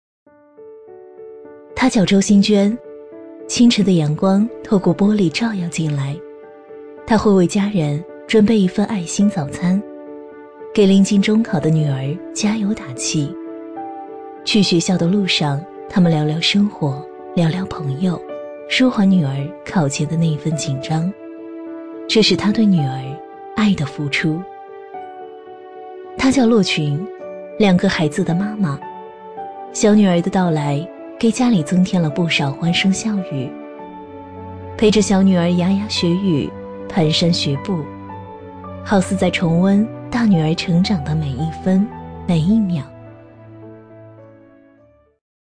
A类女50